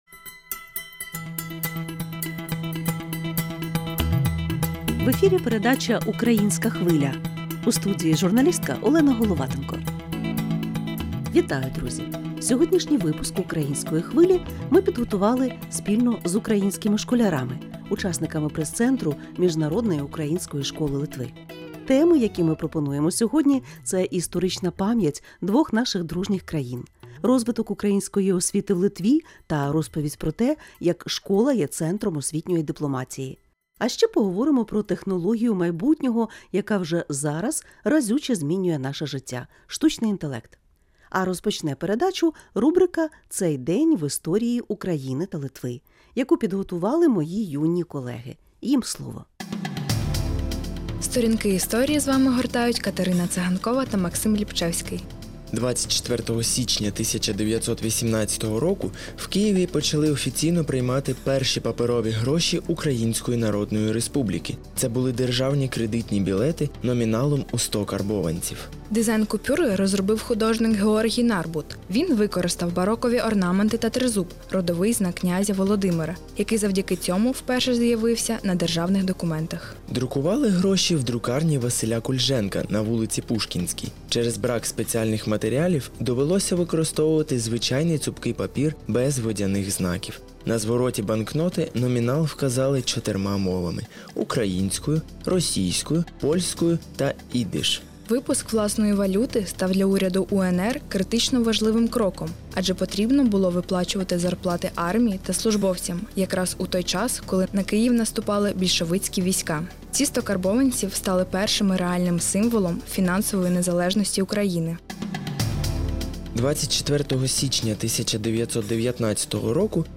У передачі «Українська Хвиля» на радіо LRT Klasika слухайте особливий випуск, підготований спільно з учнями пресцентру Міжнародної української школи Литви.